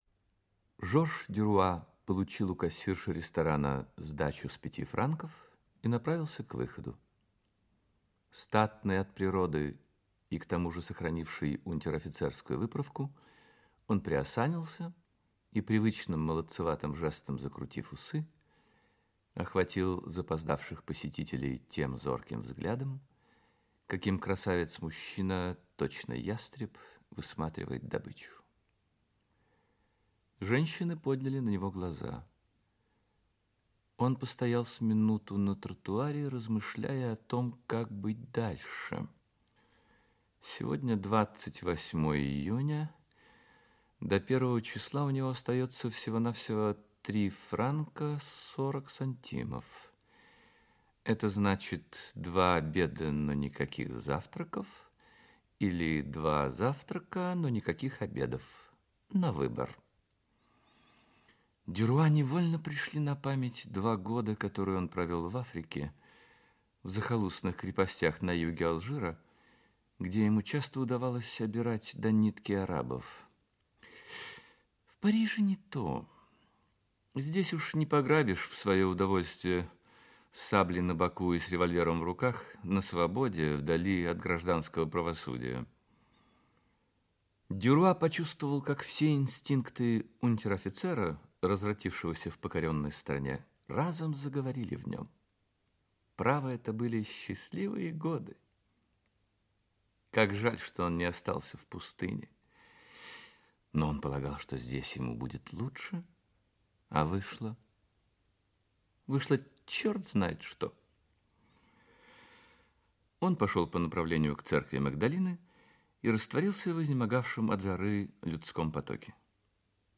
Аудиокнига Милый друг (спектакль) | Библиотека аудиокниг
Aудиокнига Милый друг (спектакль) Автор Ги де Мопассан Читает аудиокнигу Юрий Яковлев.